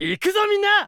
File:Fox voice sample SSBU JP.oga
Fox_voice_sample_SSBU_JP.oga.mp3